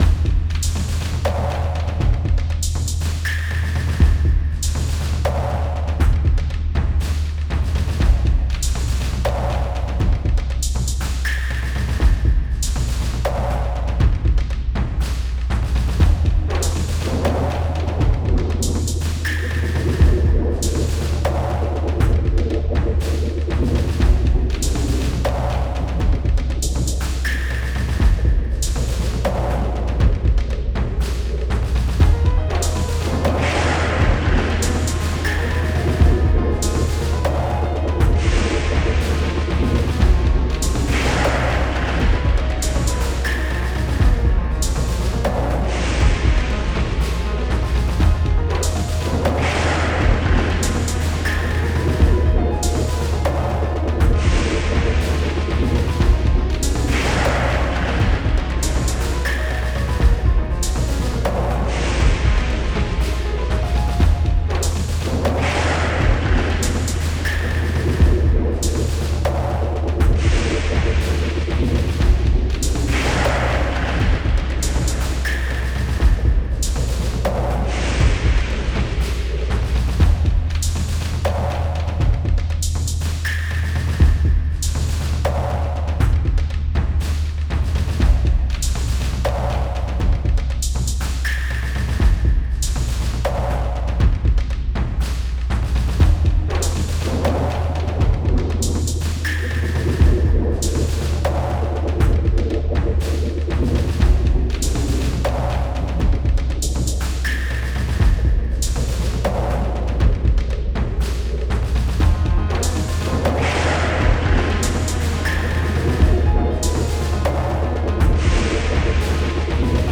Ambient thunder something something..